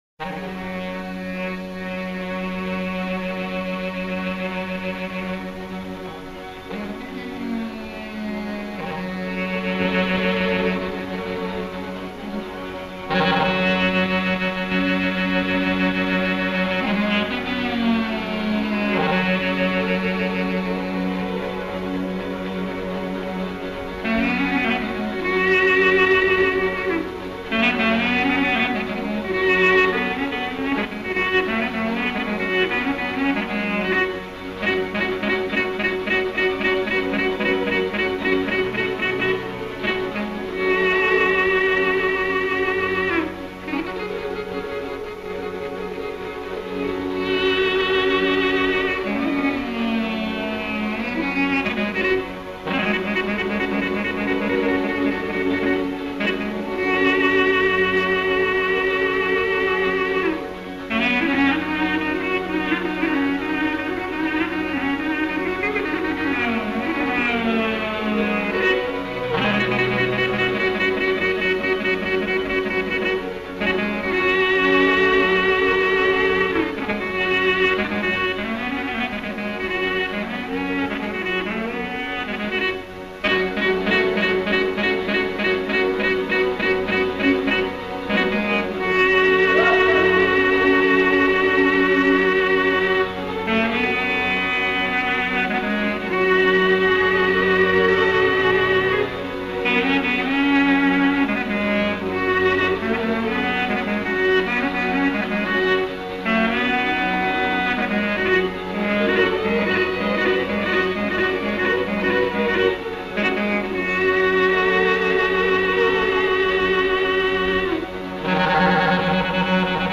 Kabaja e tij është dypjesëshe: kaba dhe valle e gëzuar.
Pjesa e dytë, vallja, afron në këndvështrimin tematik me Përmetin, kryesisht e ndërtuar si valle e rëndë dhe e shtruar burrash.
me violinë dhe këngëtar
me llautë.